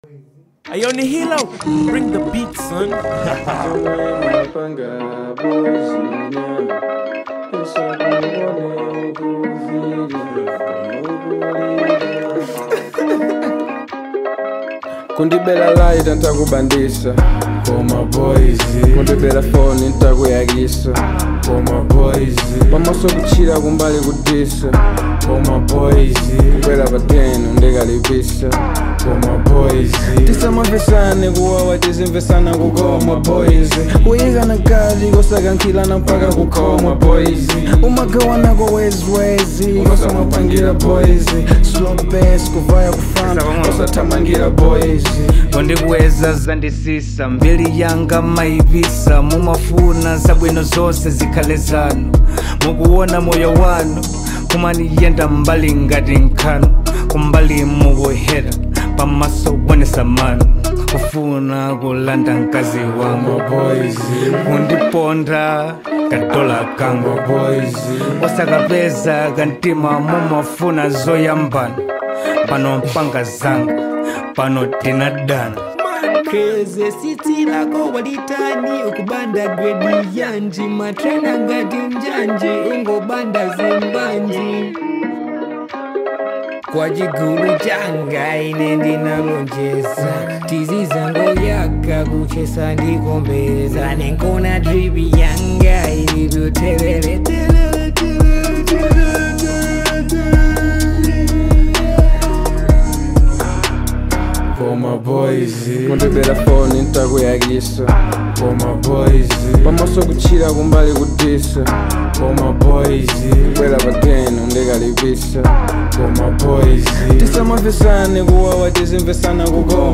Genre : Hiphop/Trap